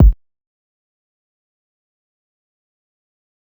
rack kick me n southside cooked up.wav